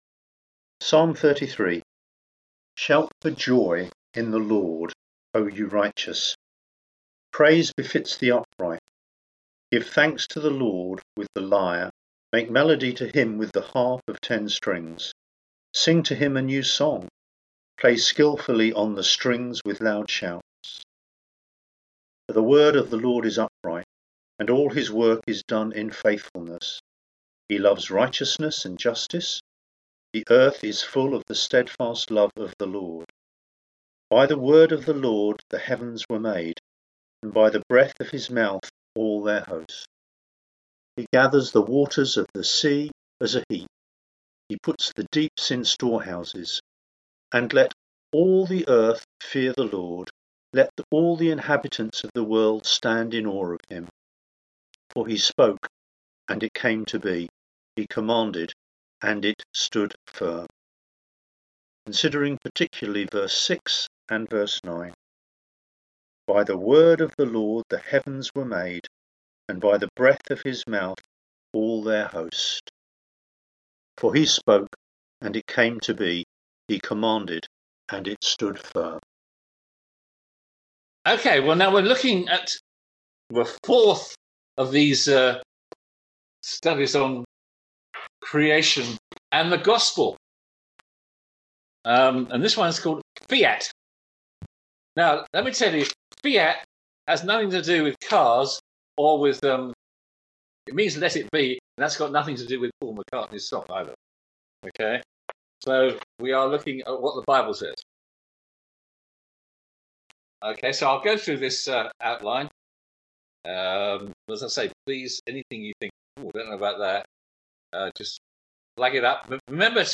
On-Line Sunday Service